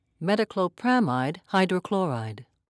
(met-oh-kloe-pra'mide)